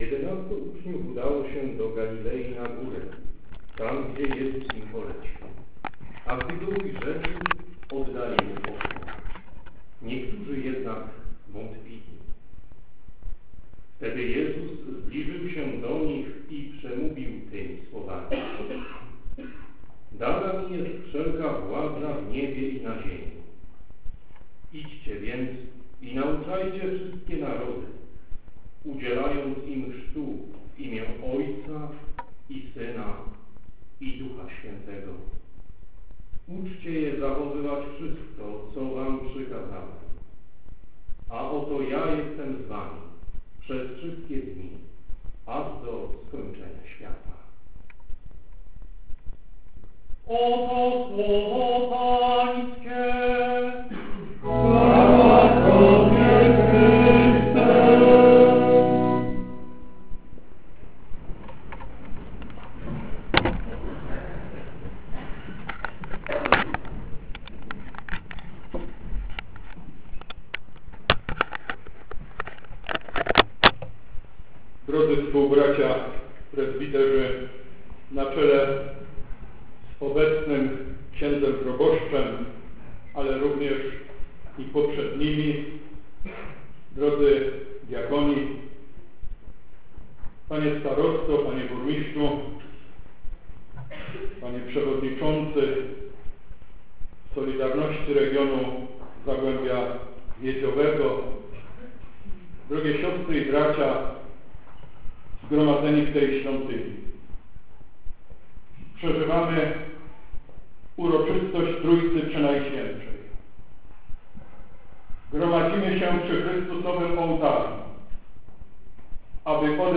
W tym roku wierni już po raz si�dmy zgromadzili się, aby uczcić pamięć błogosławionego.